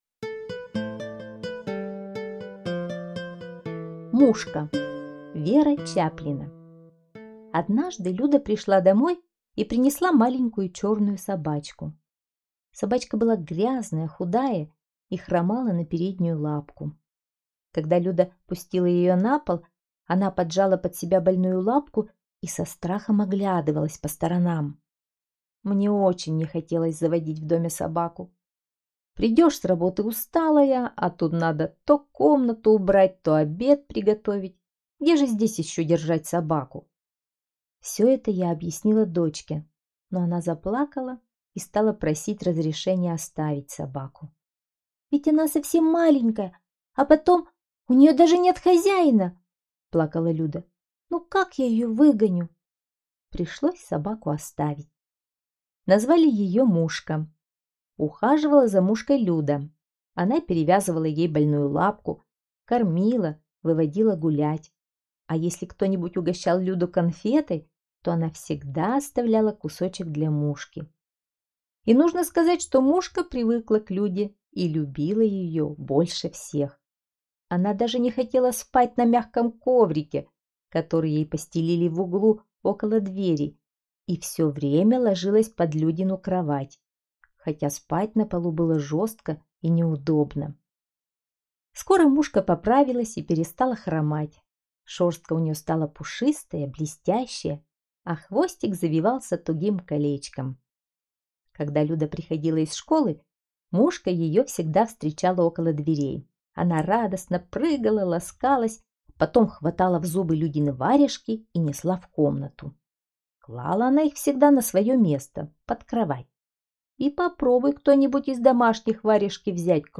Аудиорассказ «Мушка»